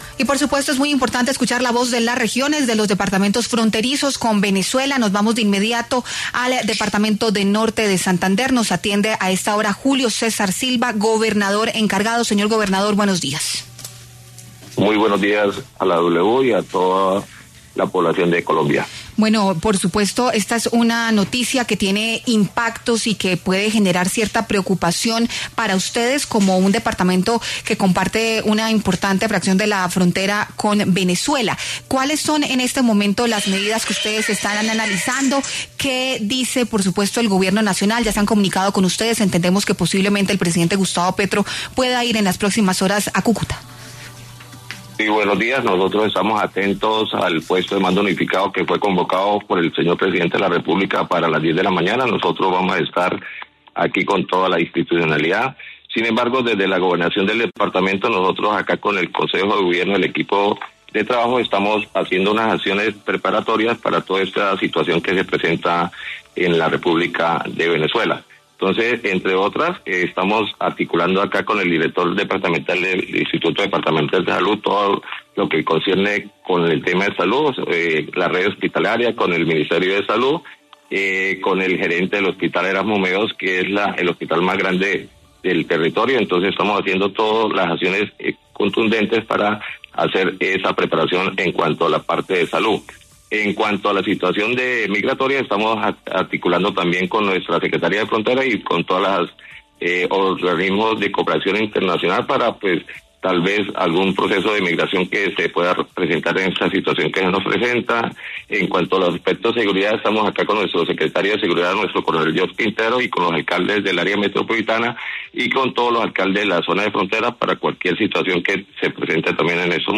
El gobernador encargado de Norte de Santander, Julio César Silva, habló con W Radio para dar un reporte de la situación de los pasos fronterizos entre Colombia y Venezuela luego de la operación militar de Estados Unidos con la que capturaron a Nicolás Maduro, líder del chavismo.